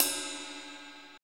CYM KLB RI0F.wav